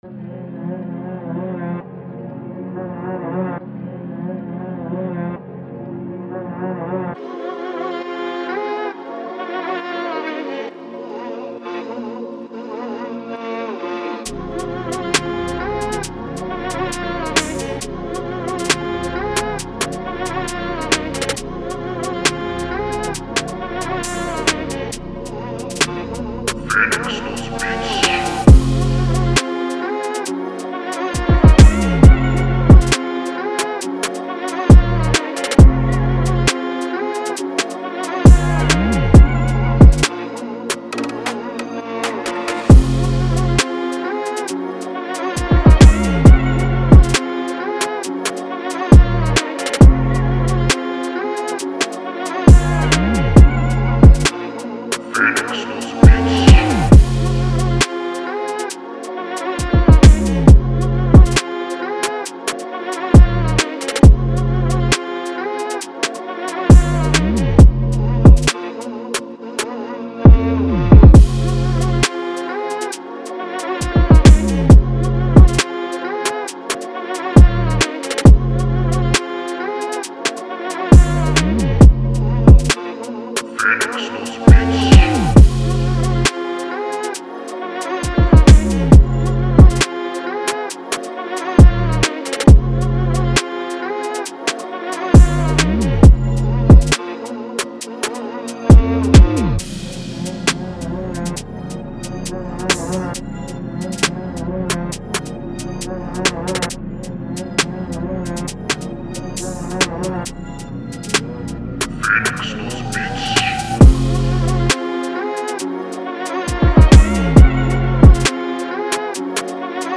Dark Drill Instrumental